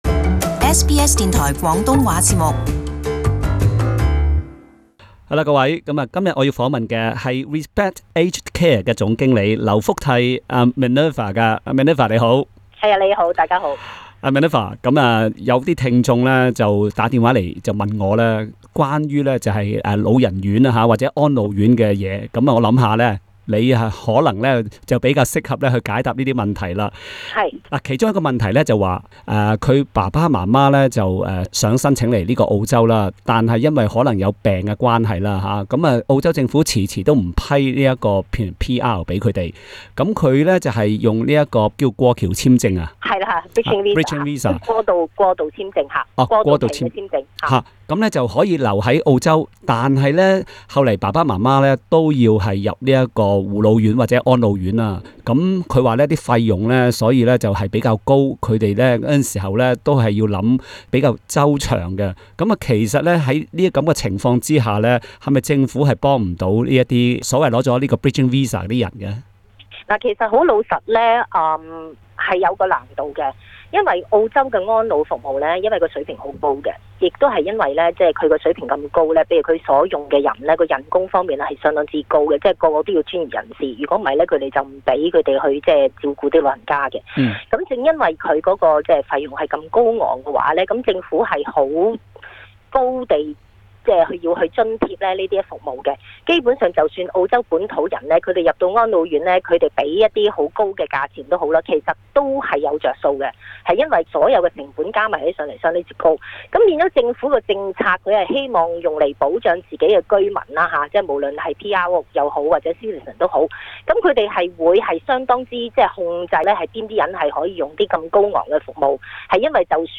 【社區專訪】 Medicare卡對入住護老院起重要作用